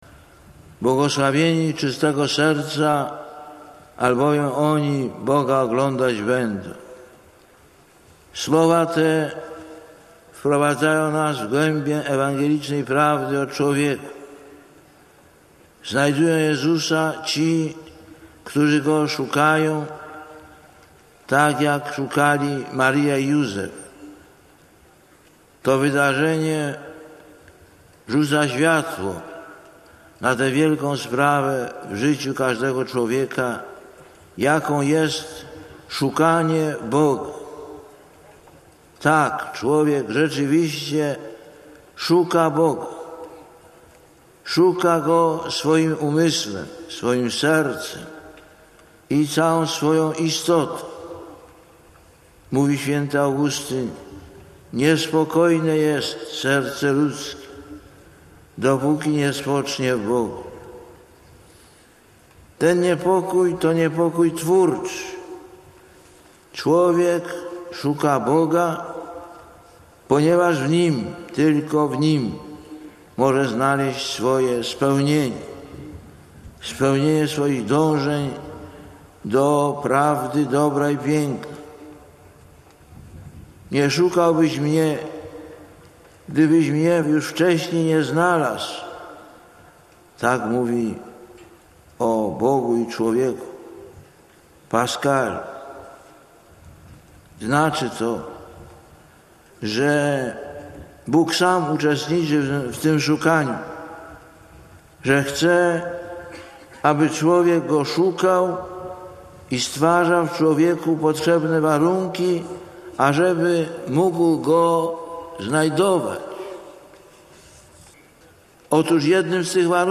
Lektor: Z homilii Ojca Świętego Jana Pawła II (Sandomierz, 12 czerwca 1999 –